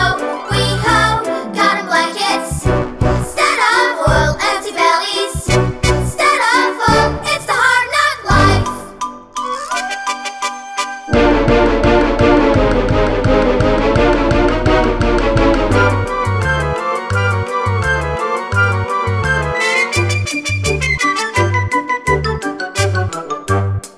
Soundtracks